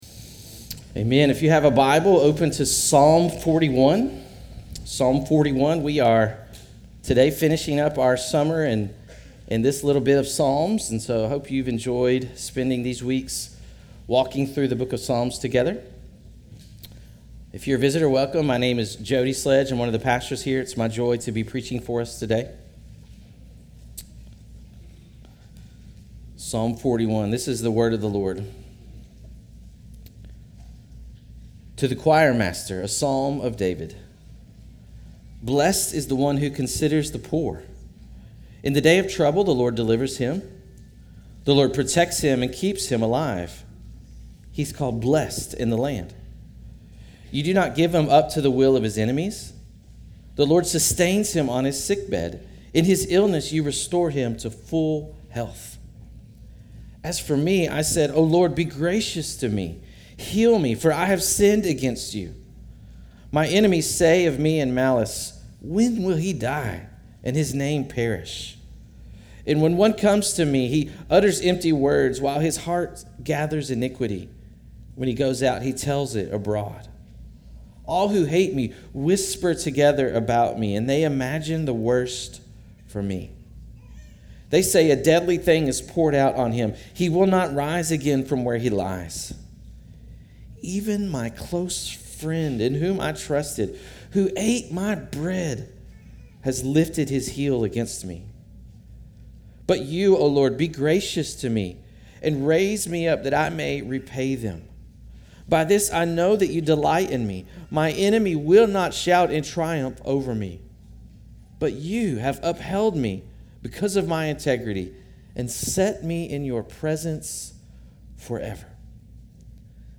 Christ Fellowship Sermons